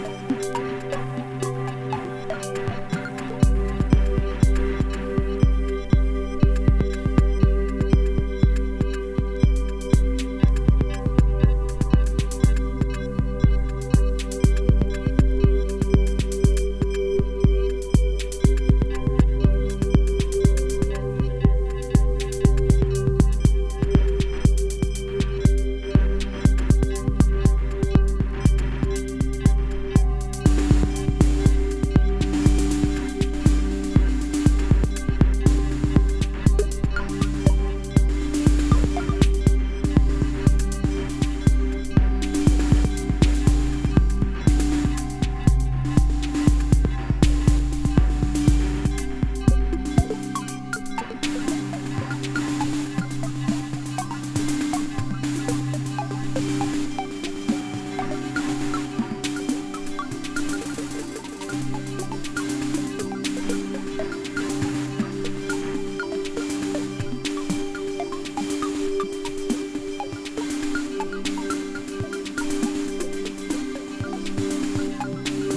歪んだAmbient、Raw House、Drone、深海Electroなど。